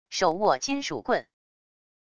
手握金属棍wav音频